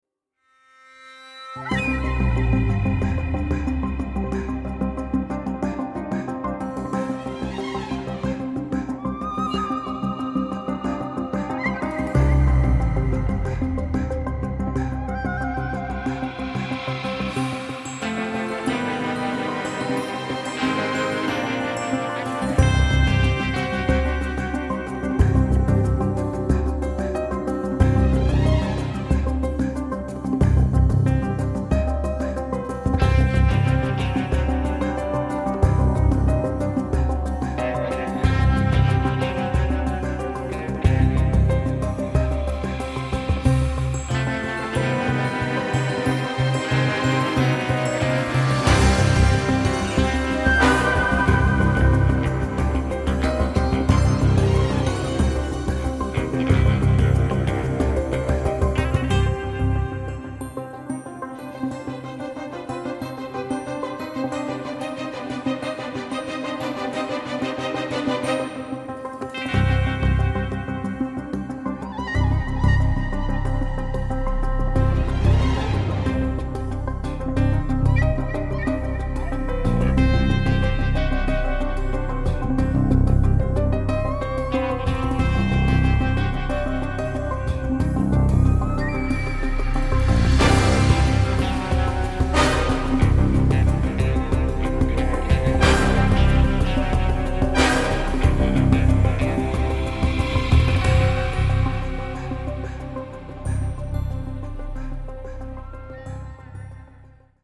a delicate and quirky score